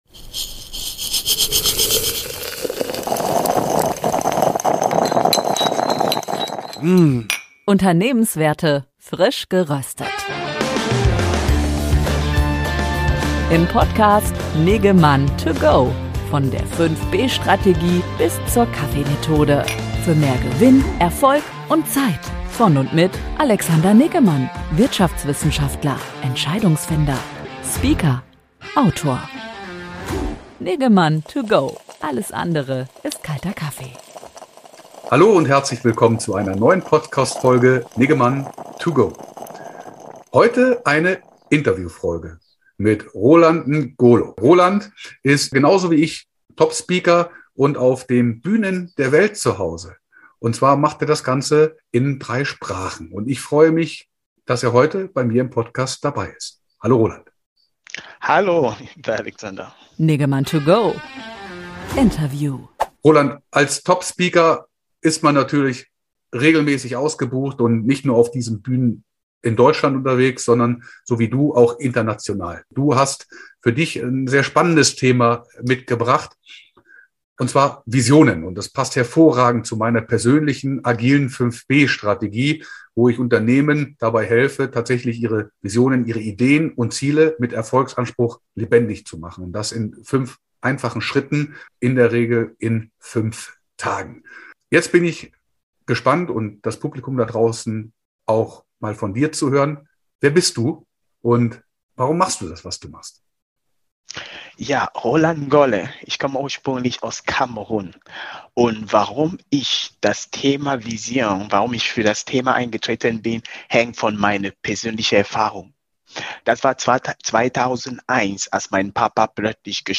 Ein sehr inspirierendes Interview.